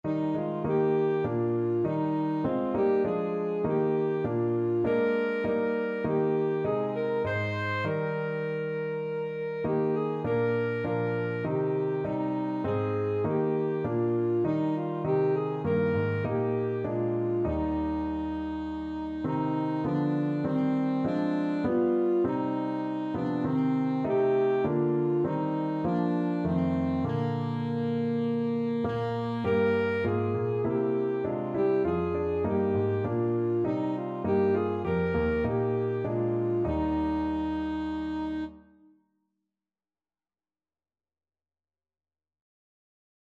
Christmas Christmas Alto Saxophone Sheet Music It Came Upon the Midnight Clear
Alto Saxophone
Eb major (Sounding Pitch) C major (Alto Saxophone in Eb) (View more Eb major Music for Saxophone )
4/4 (View more 4/4 Music)
Classical (View more Classical Saxophone Music)